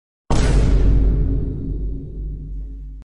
Shocked sound effect boom sound sound effects free download